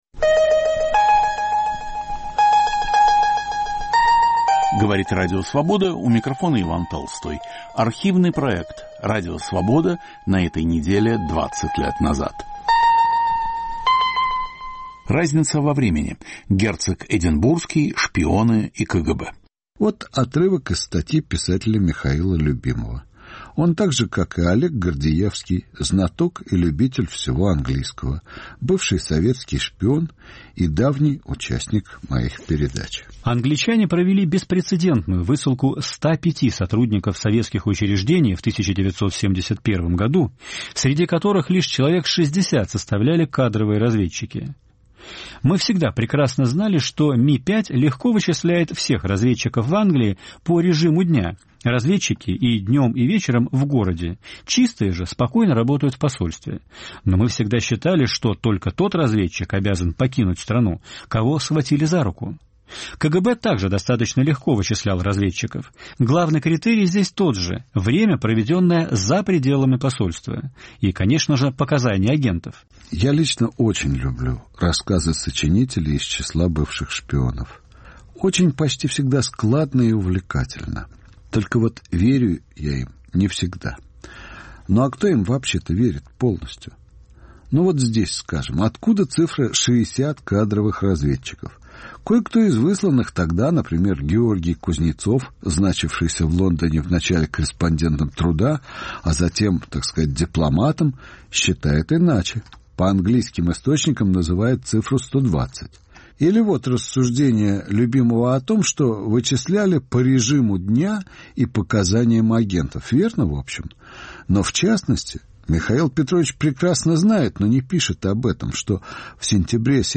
Комментаторы за круглым столом